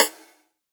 SNARE 01  -R.wav